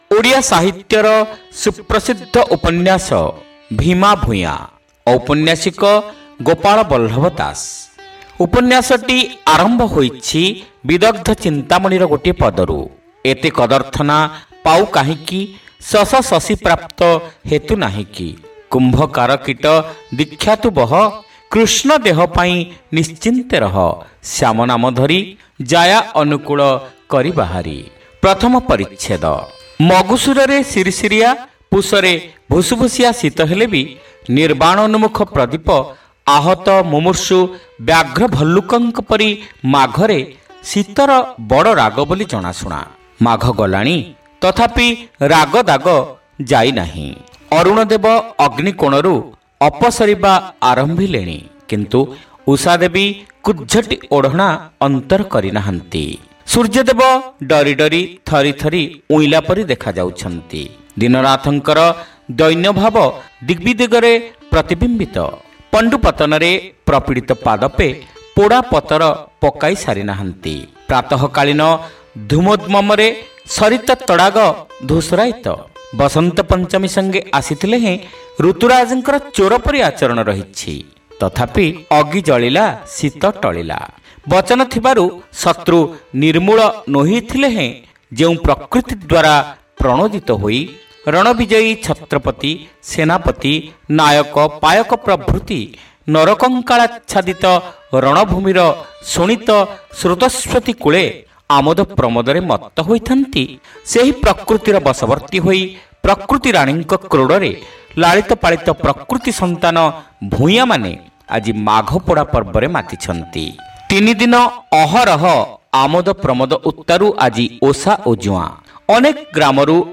ଶ୍ରାବ୍ୟ ଉପନ୍ୟାସ : ଭୀମା ଭୂୟାଁ (ପ୍ରଥମ ଭାଗ)